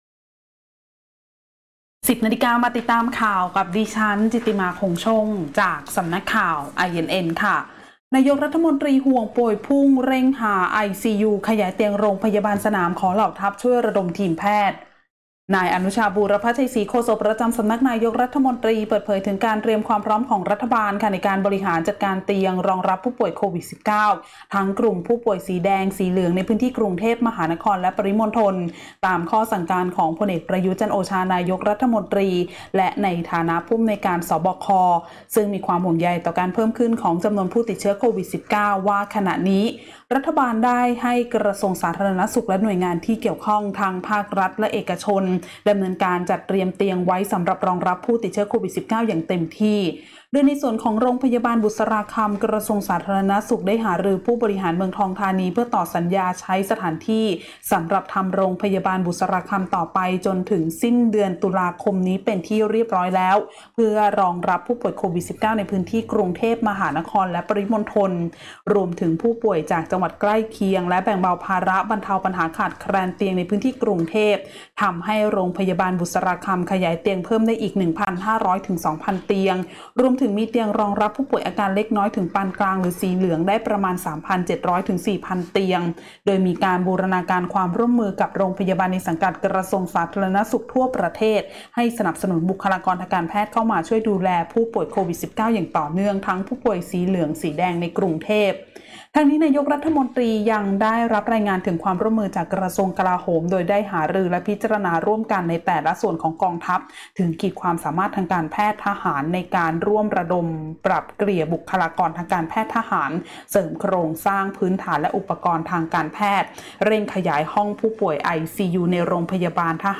คลิปข่าวต้นชั่วโมง
ข่าวต้นชั่วโมง 10.00 น.